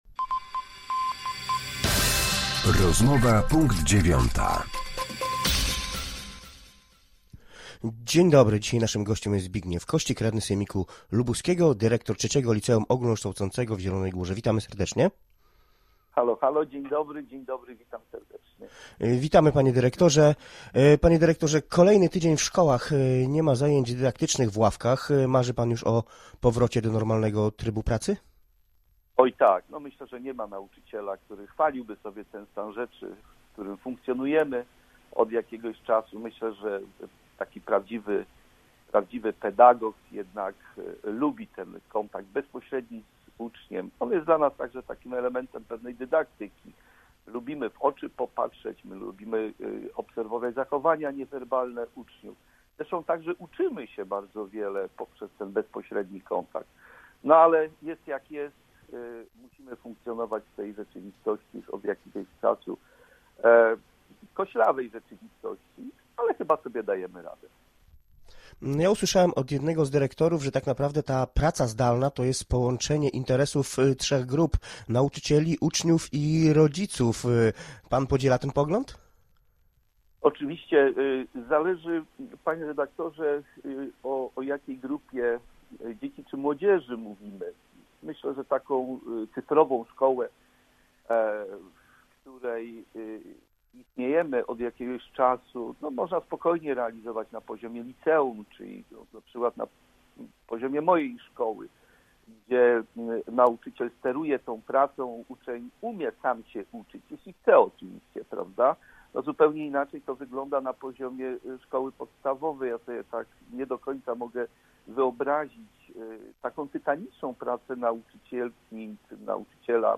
Z radnym sejmiku województwa lubuskiego, klub PiS rozmawia